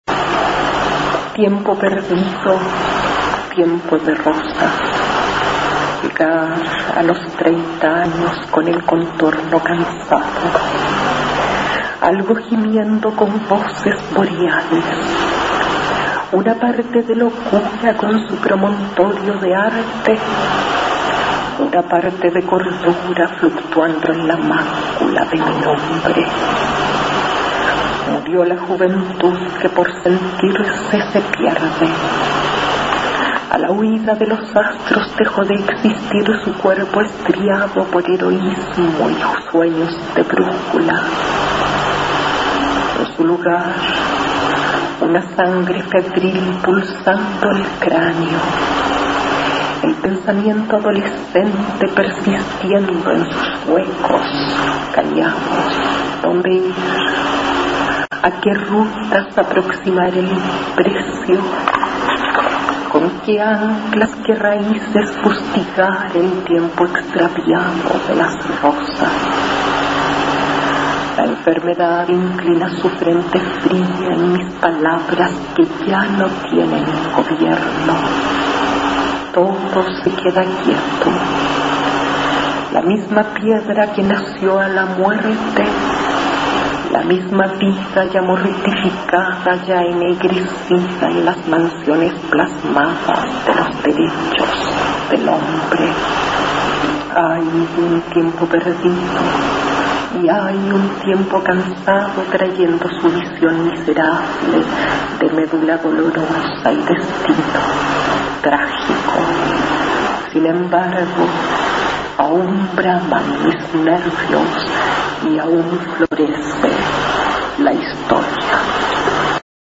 Aquí se puede escuchar a la autora nacional Astrid Fugellie recitando su poema Tiempo perdido, tiempo de rosas, del libro "Las jornadas del silencio" (1984).
Poema